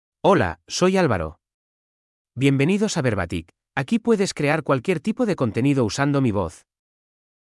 AlvaroMale Spanish AI voice
Alvaro is a male AI voice for Spanish (Spain).
Voice sample
Listen to Alvaro's male Spanish voice.
Male
Alvaro delivers clear pronunciation with authentic Spain Spanish intonation, making your content sound professionally produced.